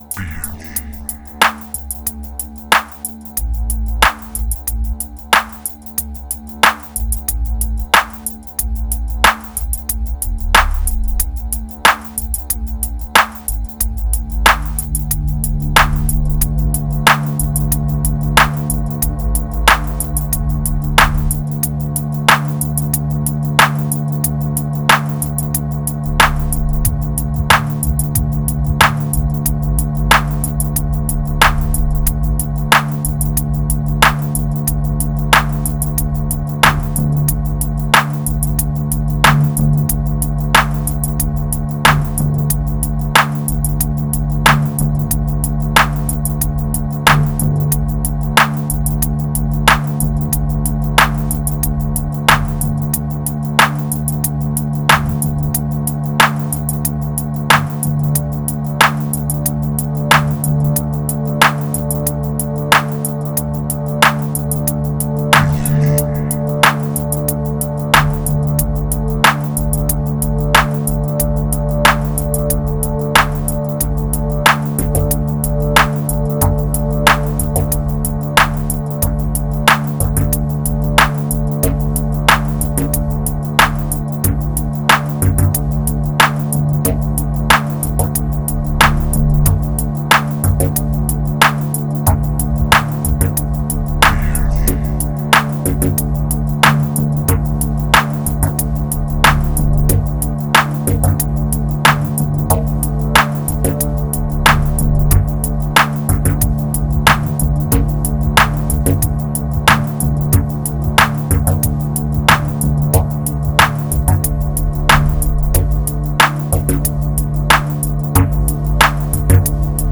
929📈 - 54%🤔 - 92BPM🔊 - 2020-07-19📅 - 763🌟
Dark Headz Bass Opera Moods Spectrum Blunt Ashes Relief